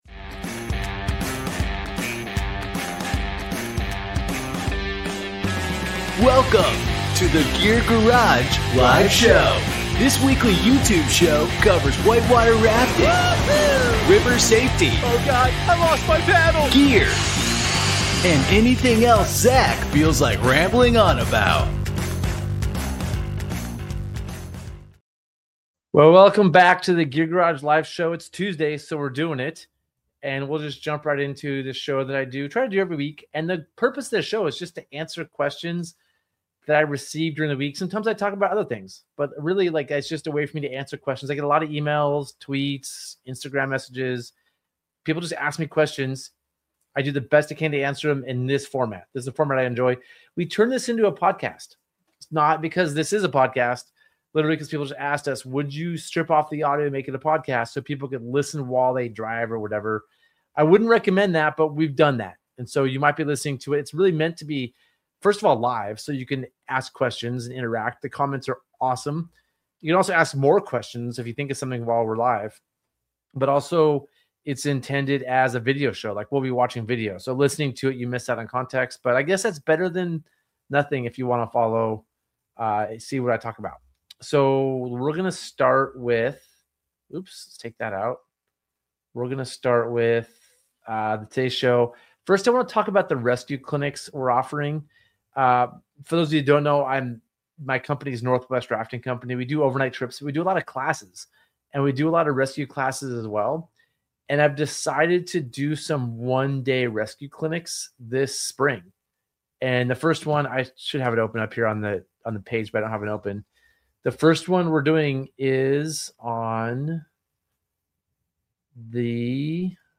This podcast is the audio version of the Gear Garage Live Show